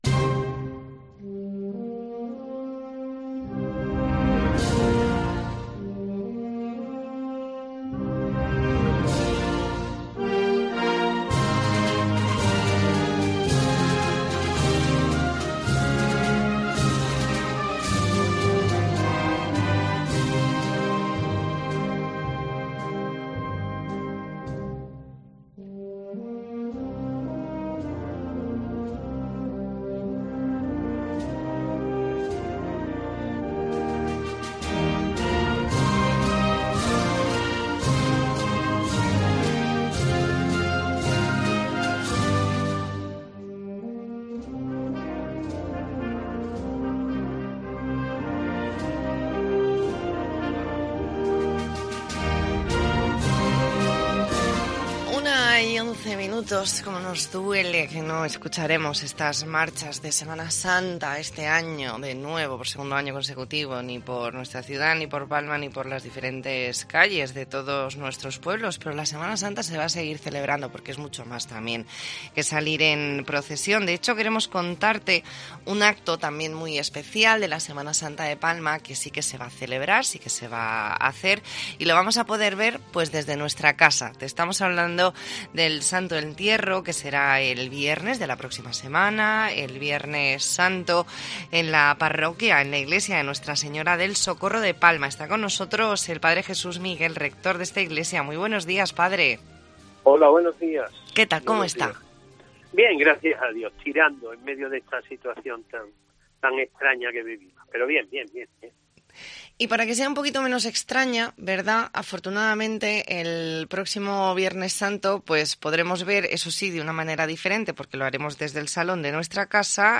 Entrevista en La Mañana en COPE Más Mallorca, martes 23 de marzo de 2021.